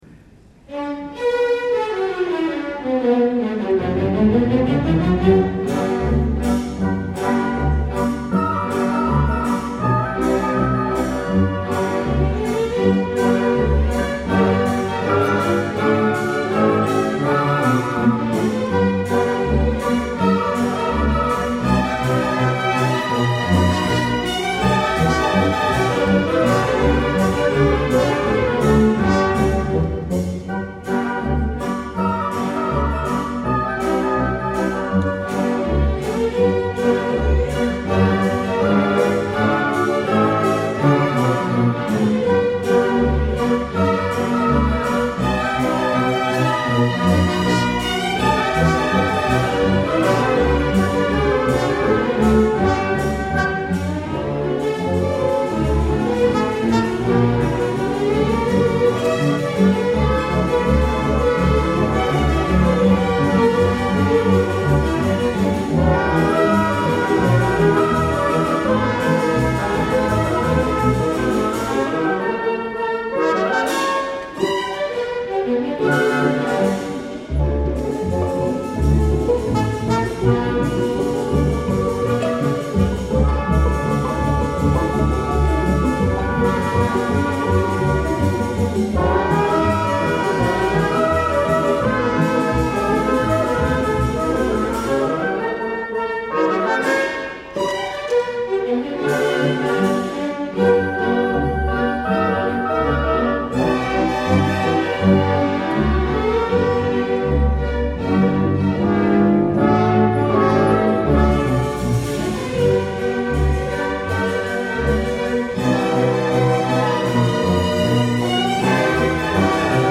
Easy winners – για Ορχήστρα (live)
Συμφωνική Ορχήστρα Κύπρου Μαέστρος